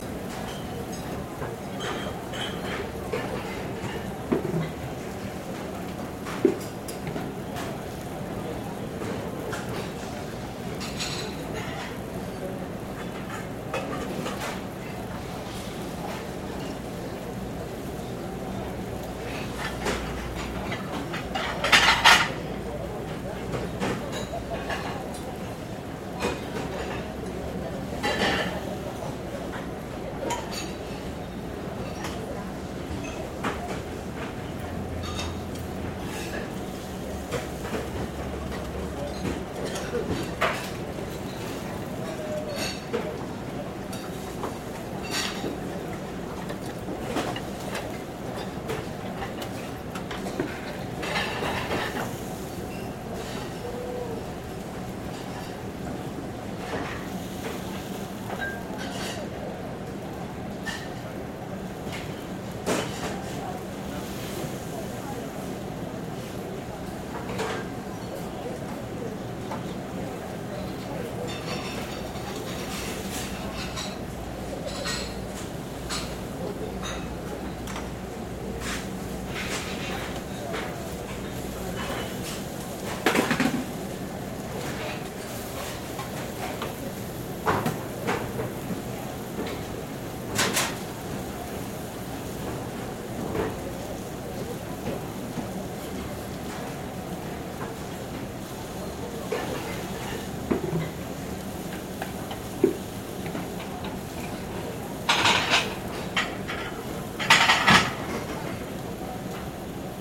Звуки повара, кухни
Атмосферный звук ресторана с отдаленными шумами кухонной работы